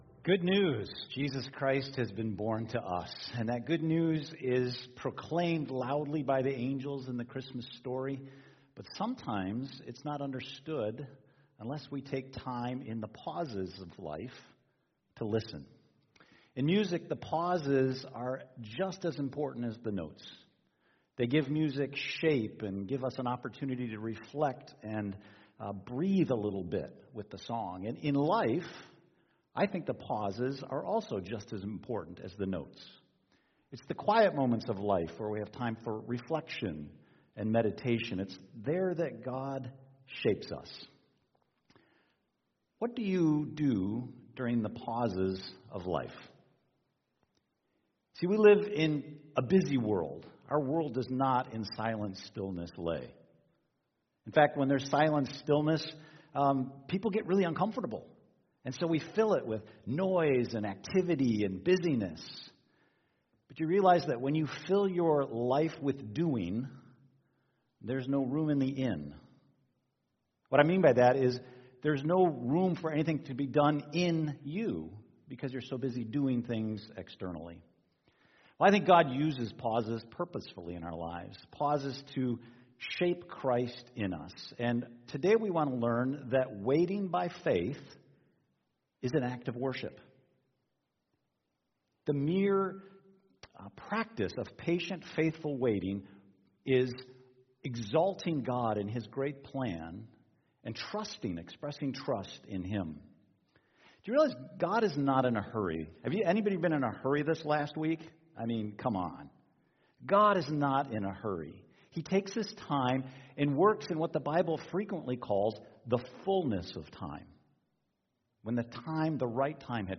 The Character of Worship Service Type: Sunday Morning « Let It Go!